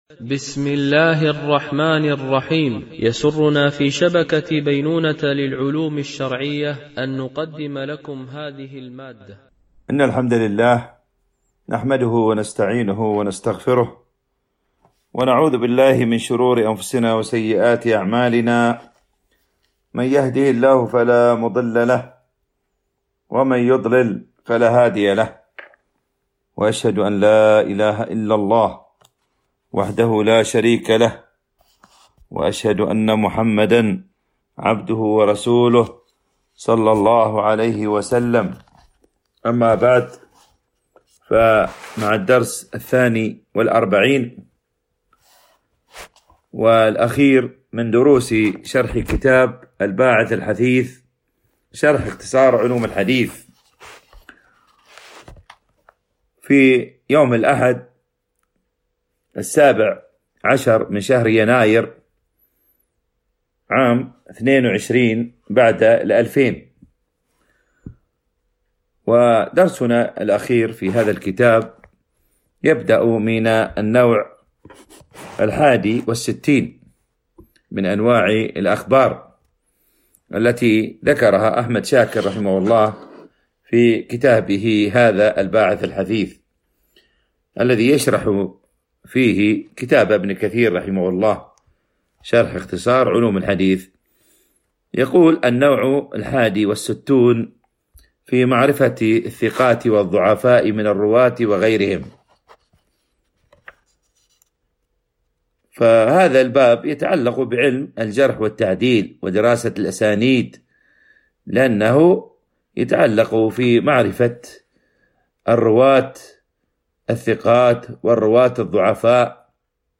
شرح كتاب الباعث الحثيث شرح اختصار علوم الحديث - الدرس 42 والأخير ( معرفة الثقات والضعفاء من الرواة ومن اختلط ... وأوطان الرواة )